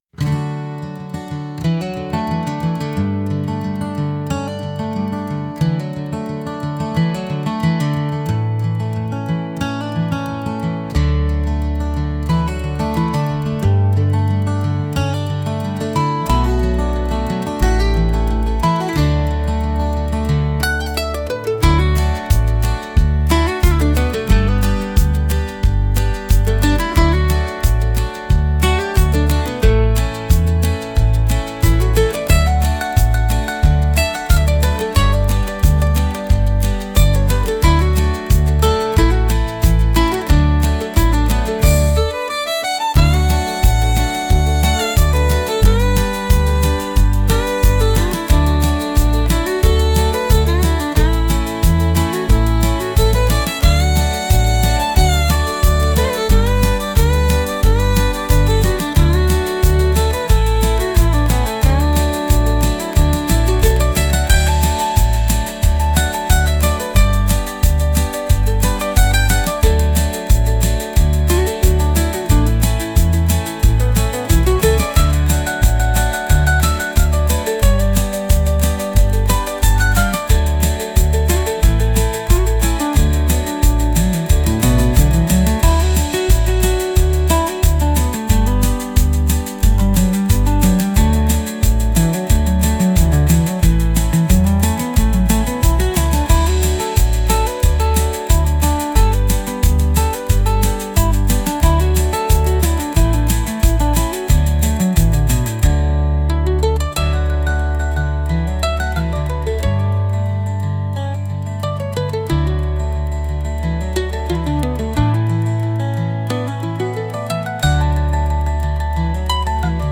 Genre: Country Mood: Acoustic Editor's Choice